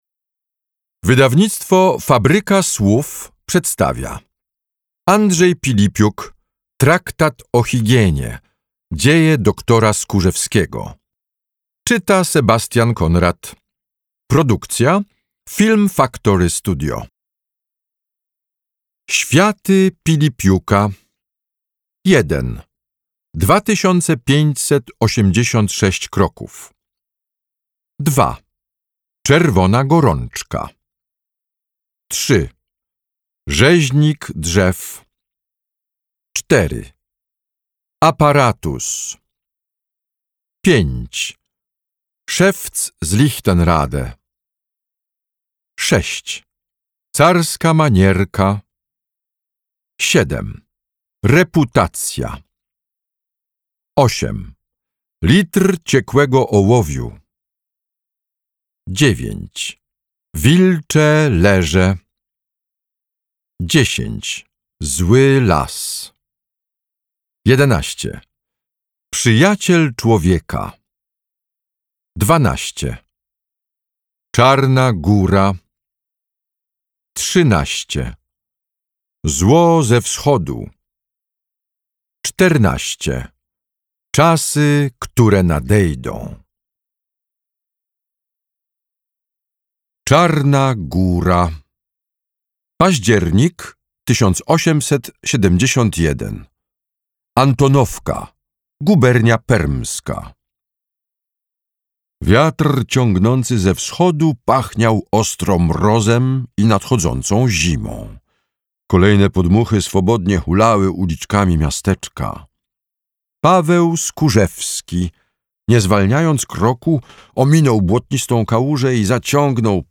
Traktat o higienie. Dzieje doktora Skórzewskiego - Andrzej Pilipiuk - audiobook